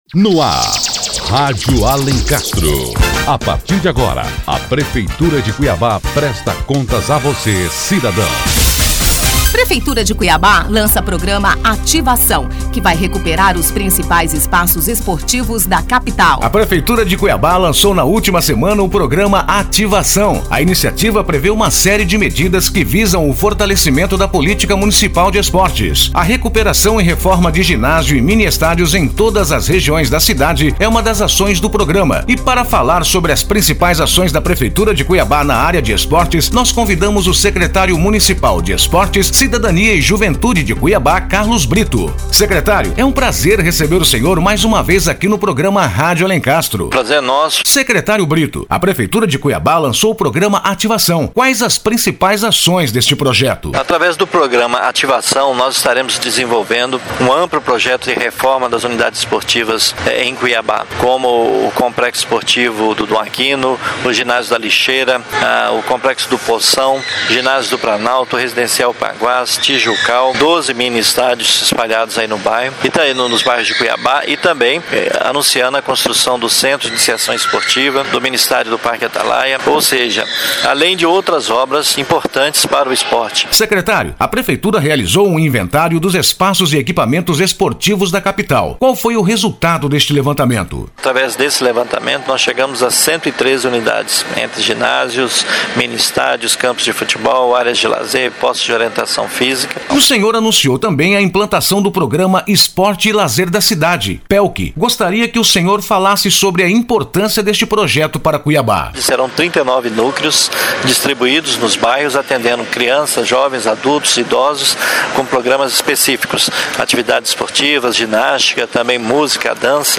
Notícias / 90º Programa 04 de Abril de 2014 14h40 Conheça melhor o Programa Ativação O Secretário Municipal de Esportes, Cidadania e Juventude, Carlos Brito, fala sobre o Programa Ativação e também sobre outras ações da Prefeitura de Cuiabá no setor de esportes.